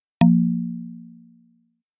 sfx_show_upgrade_card.mp3